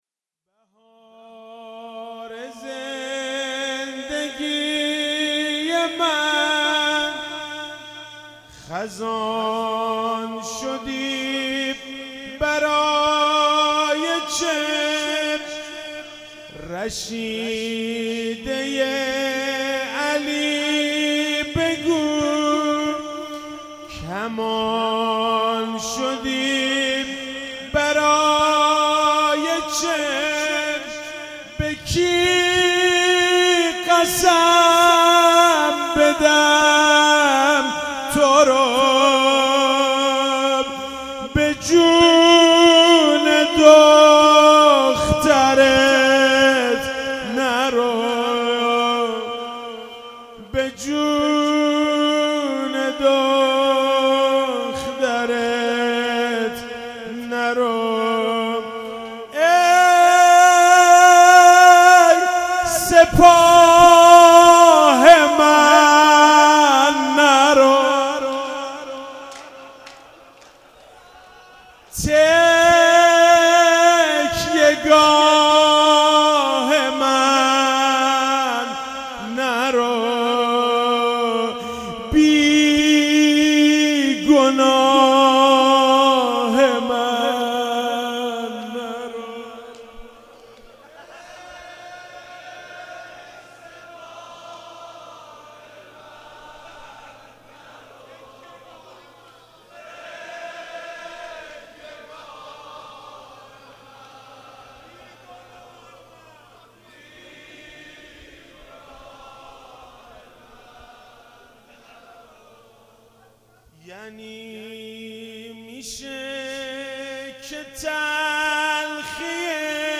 شب 5 فاطمیه 95 - روضه - بهار زندگی من خزان شدی